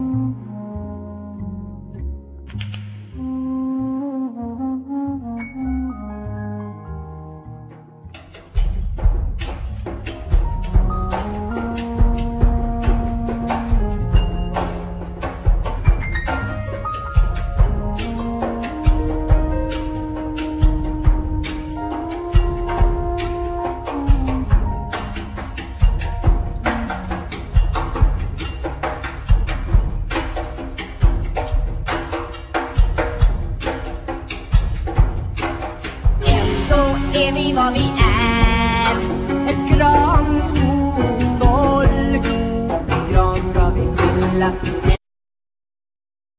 Vocals
Fiddle
Saxophone,Flute
Percussion
Bass
Piano,Keyboards